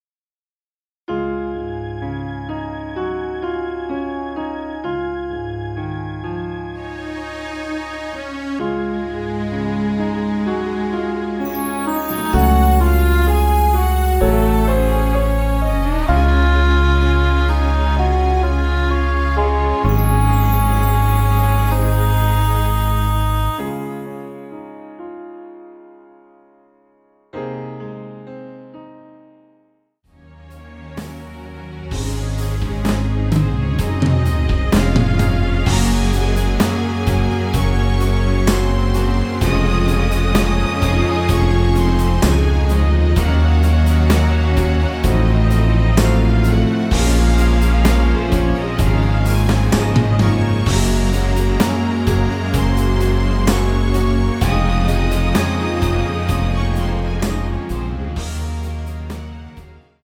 원키에서(-2)내린 MR입니다.
Db
앞부분30초, 뒷부분30초씩 편집해서 올려 드리고 있습니다.